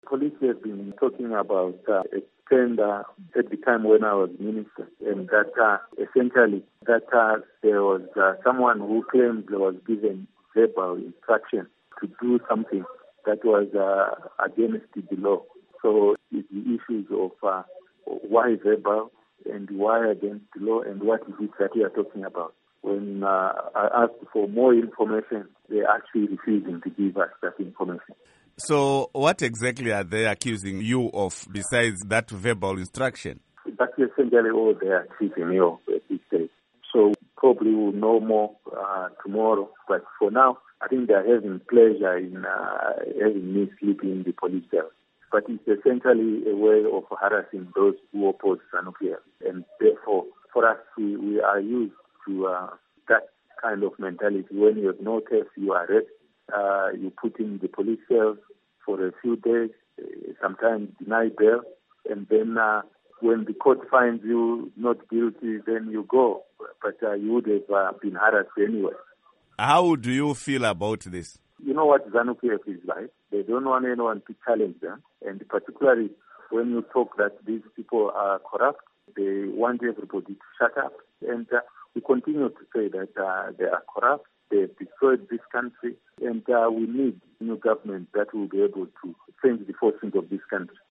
Interview With Elton Mangoma